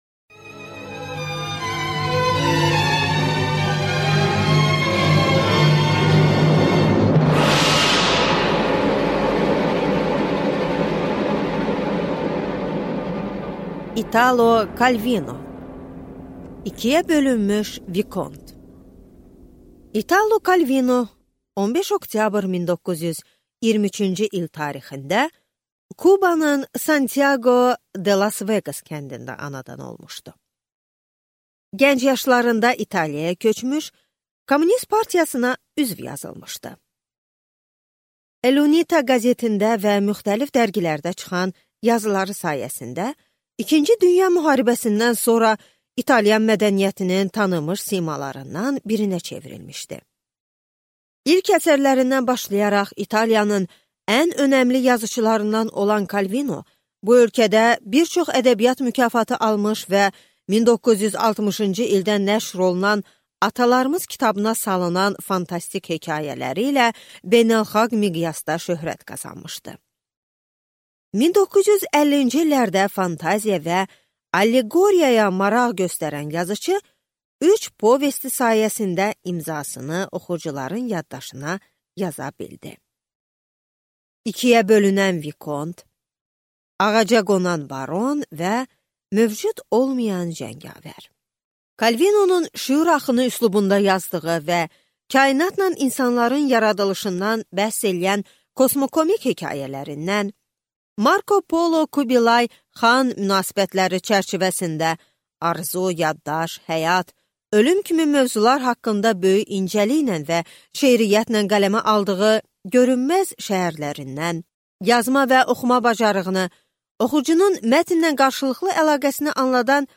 Аудиокнига İkiyə bölünmüş Vikont | Библиотека аудиокниг